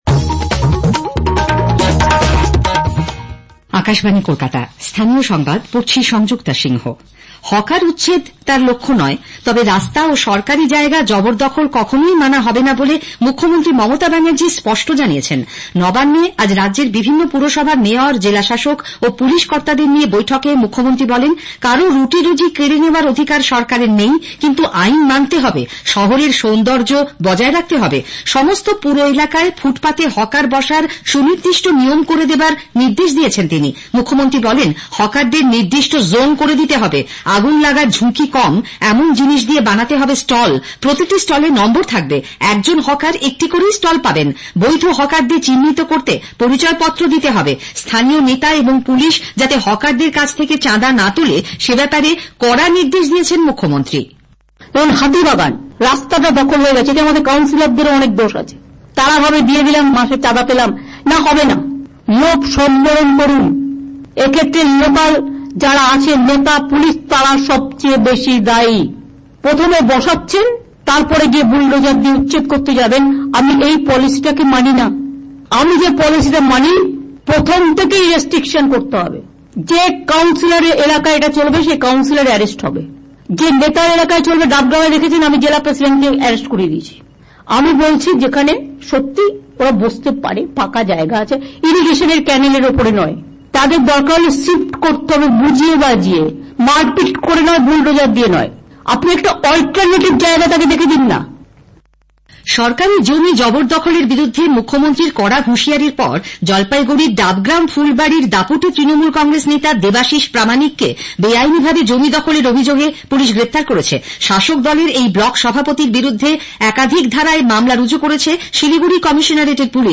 REGIONAL AUDIO NEWS-27TH JUNE,2024